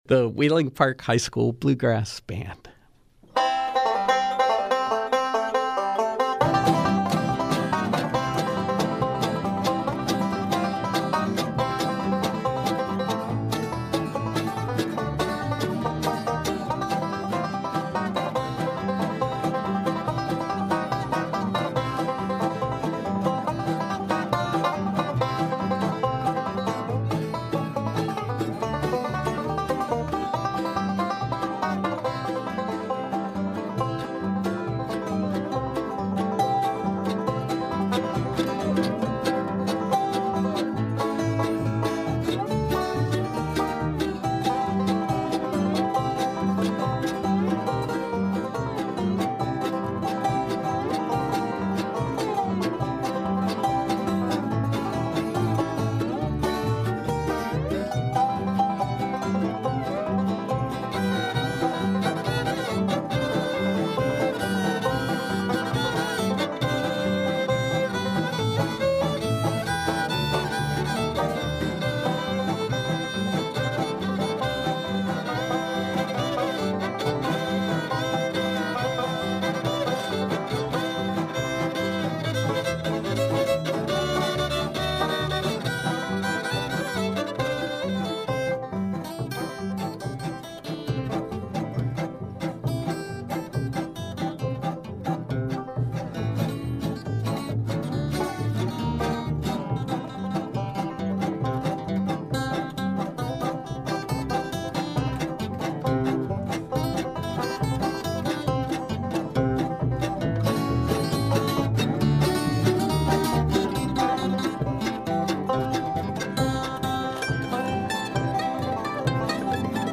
Youth Express: Wheeling Park High School Bluegrass Band
mandolin
bass
banjo
dobro
guitar
fiddle